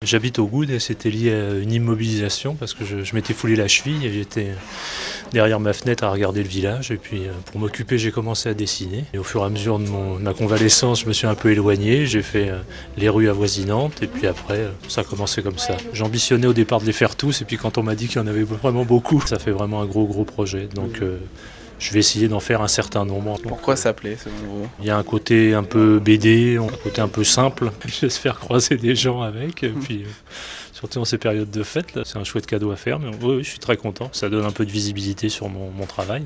Sur radio France bleue Provence…